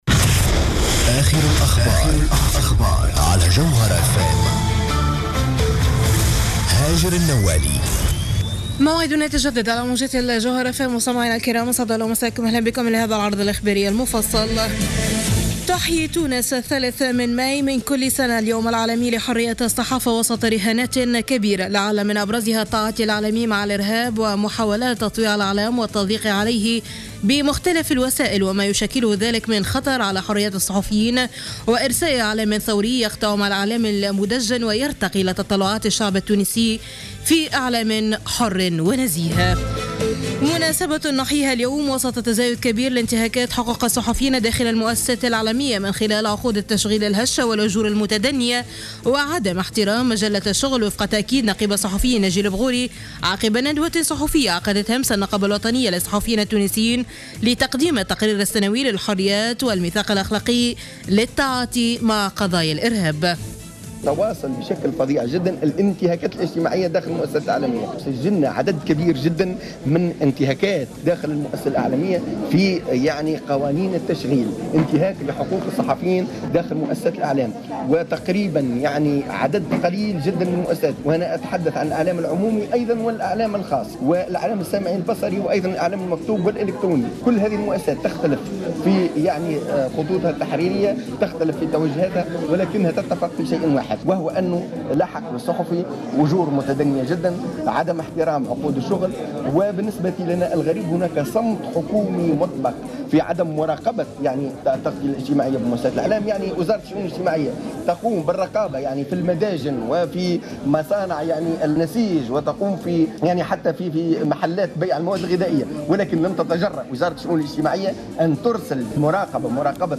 نشرة أخبار منتصف الليل ليوم الأحد 3 ماي 2015